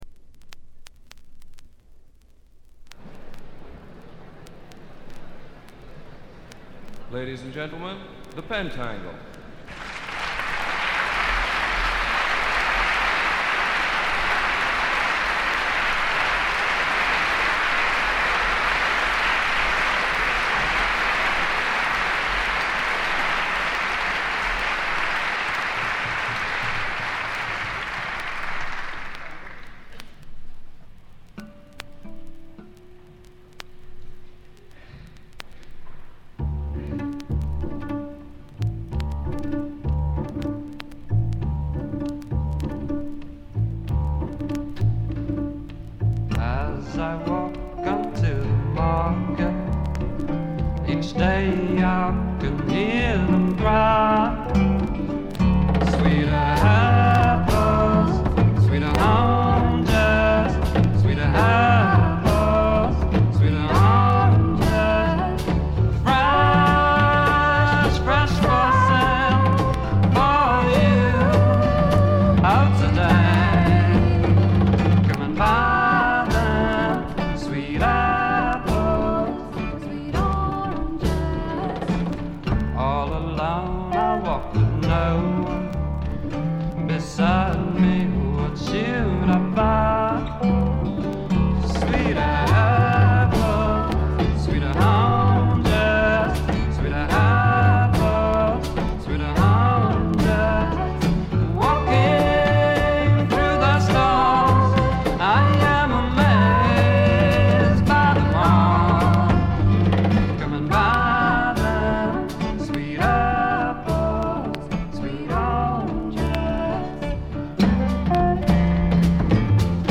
ホーム > レコード：英国 フォーク / トラッド
A面はノイズがちょっと目立ちます。
試聴曲は現品からの取り込み音源です。